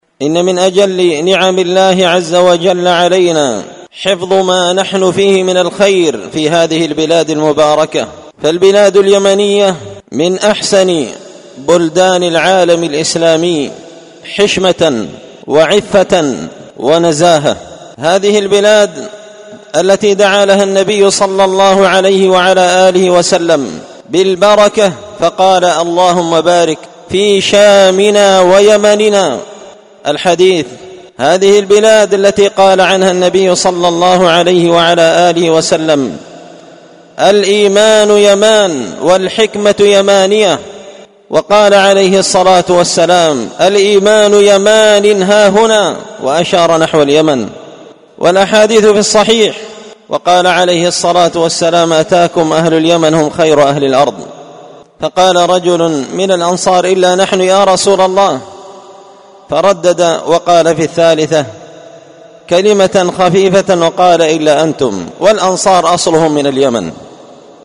سلسلة مقتطفات من خطبة جمعة بعنوان حراسة الفضيلة وحماية المجتمع من الرذيلة⏸المقتطف الأول⏸فضيلة اليمن وما فيها من الحشمة والنزاهة
دار الحديث بمسجد الفرقان ـ قشن ـ المهرة ـ اليمن